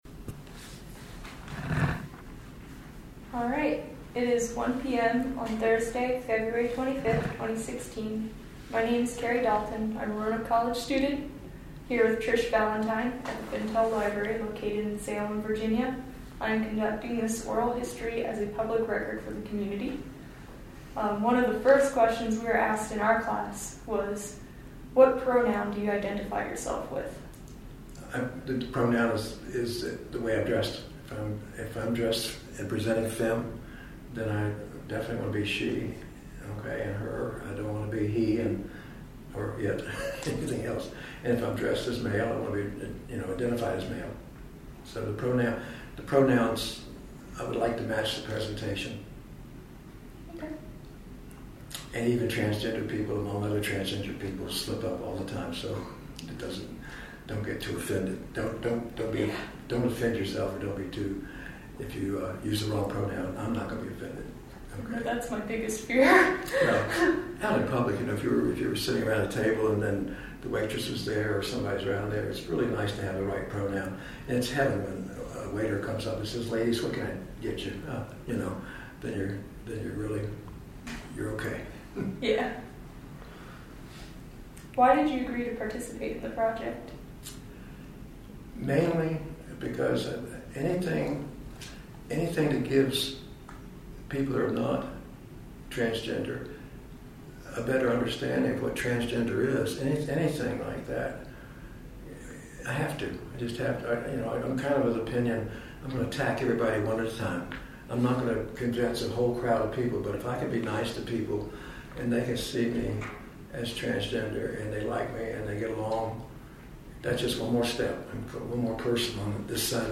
Oral History Interview
Location: Roanoke College, Fintel Library, 220 High Street, Salem, VA